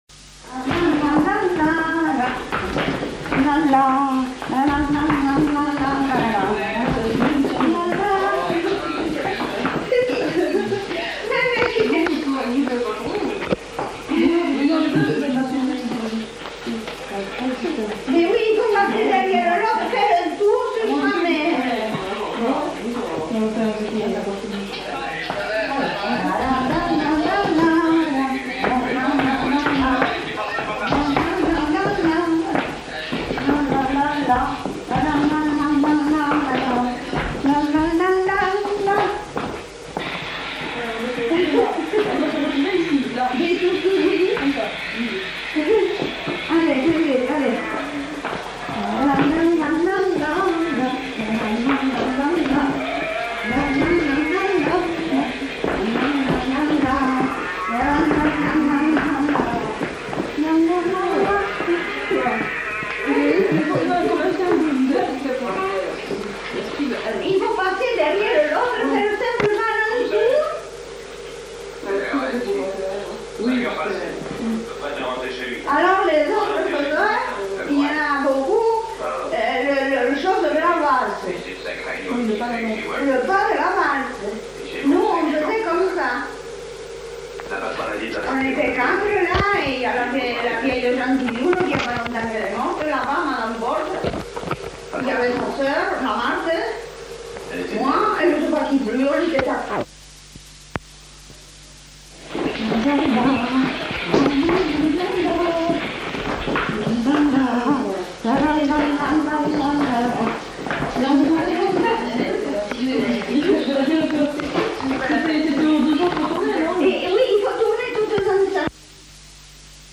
Aire culturelle : Haut-Agenais
Genre : chant
Effectif : 1
Type de voix : voix de femme
Production du son : fredonné
Danse : rondeau
Notes consultables : Fredonne l'air tout en montrant les pas.